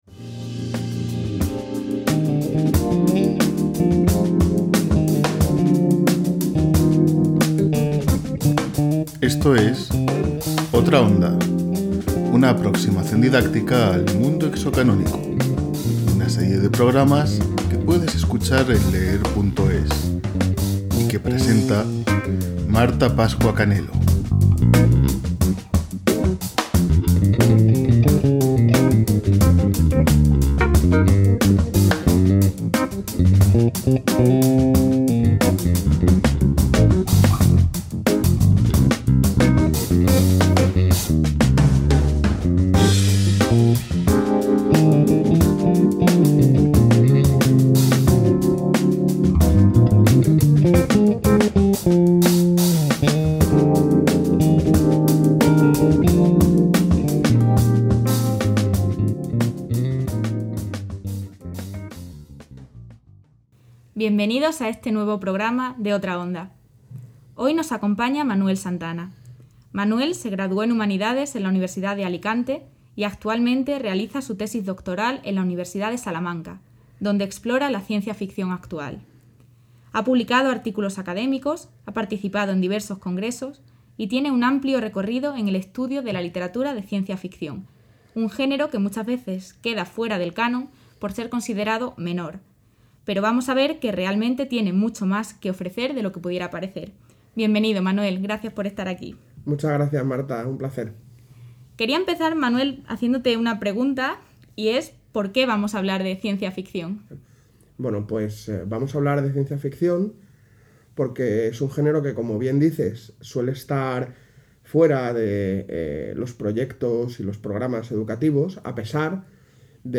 Esta serie de pódcasts ofrece a los docentes y estudiantes interesados siete capítulos en los que varios expertos de la Universidad de Salamanca son entrevistados para hablar de las ventajas y posibilidades educativas de introducir en el aula objetos culturales ajenos al canon cultural dominante.